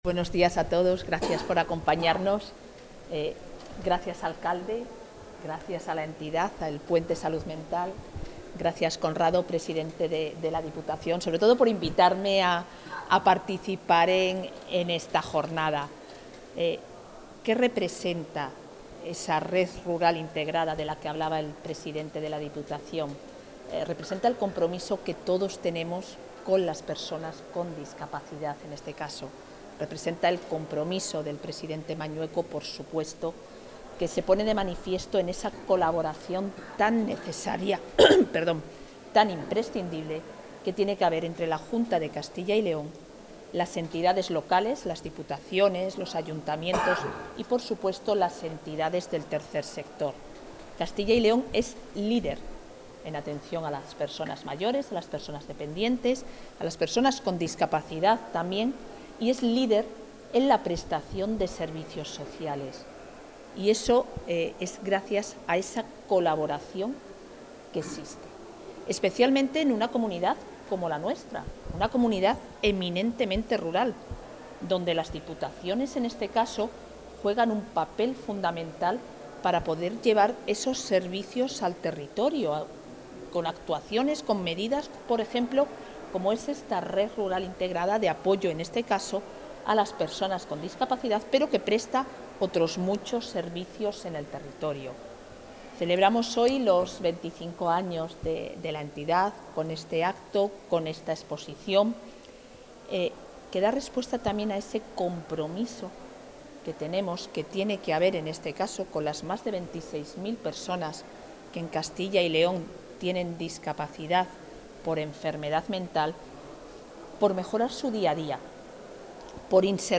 Intervención de la vicepresidenta de la Junta.
La vicepresidenta de la Junta de Castilla y León y consejera de Familia e Igualdad de Oportunidades, Isabel Blanco, ha participado esta mañana en la inauguración de la exposición colectiva con motivo del 25 aniversario del 'Taller Prelaboral Pinoduero' de Salud Mental de Tudela de Duero, en Valladolid.